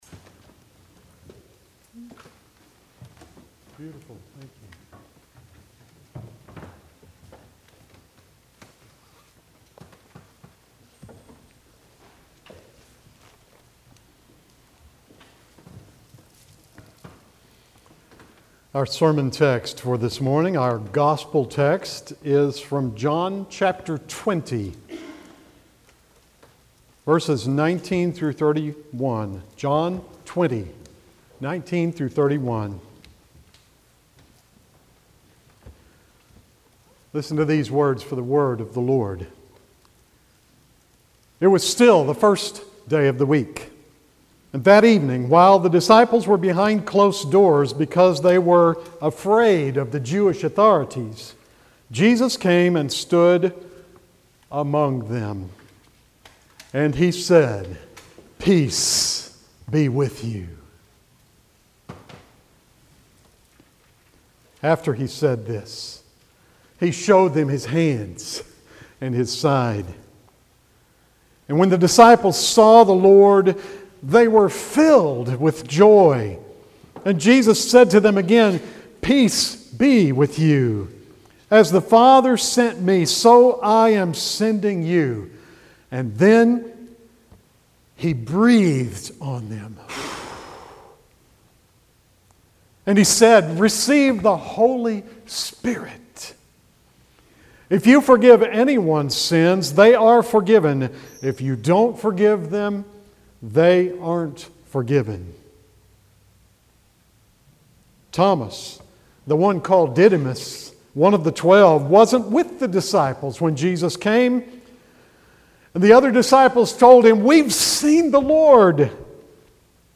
Traditional Sermon